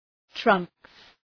Προφορά
{trʌŋks}